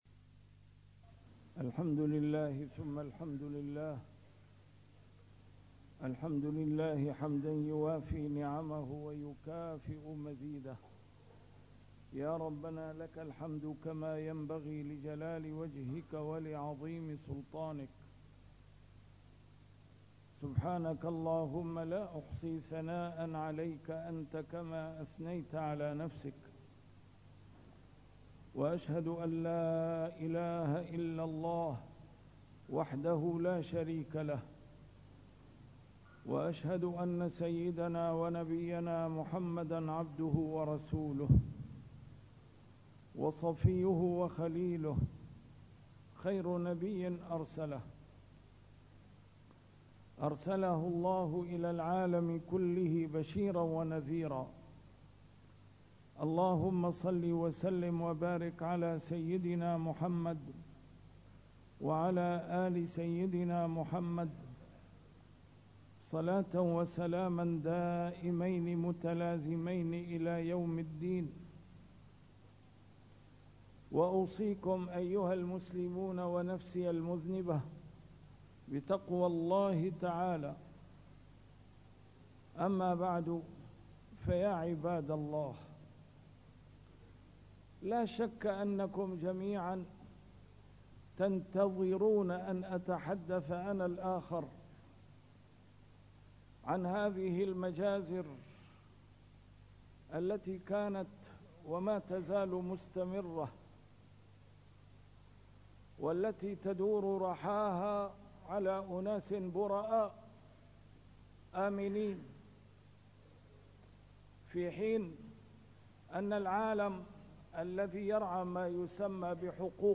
A MARTYR SCHOLAR: IMAM MUHAMMAD SAEED RAMADAN AL-BOUTI - الخطب - المسجد الأقصى يحتاج منا إلى عمل صامت دؤوب